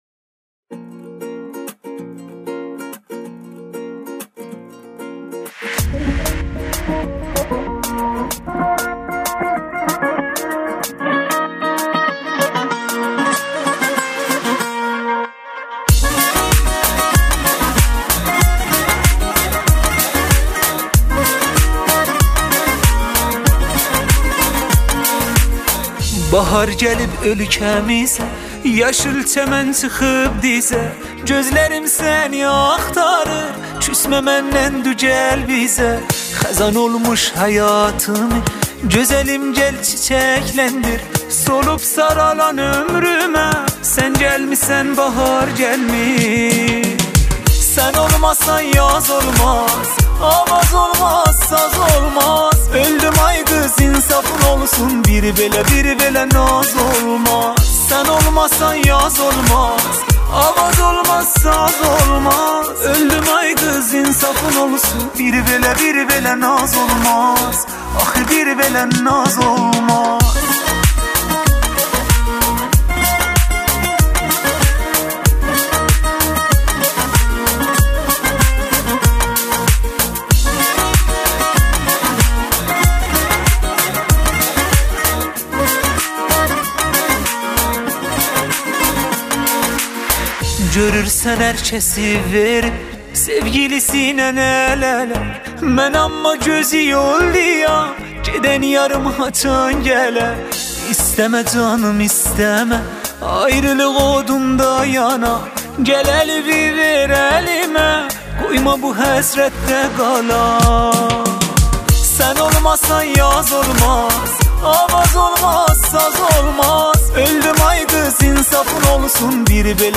موزیک ترکی آذربایجانی